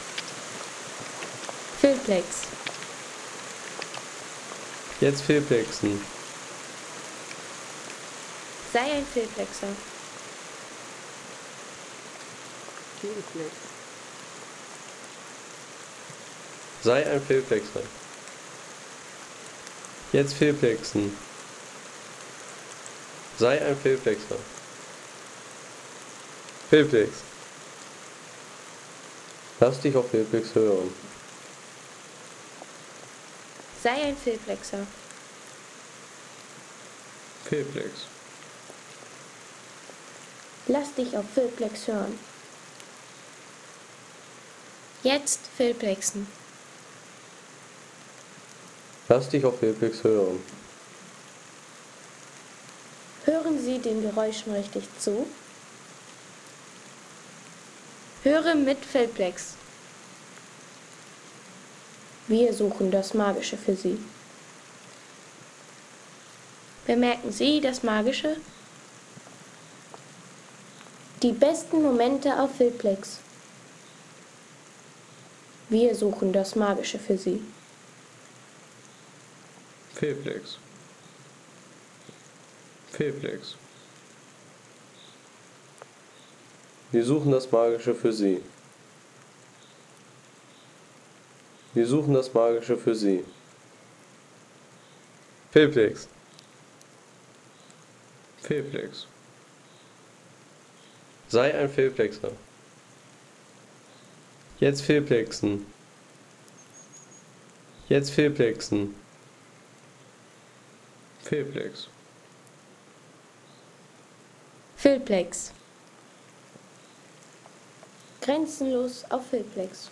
Der Regen im Nationalpark der Sächsischen Schweiz